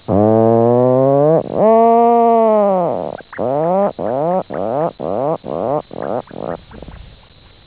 puffin.wav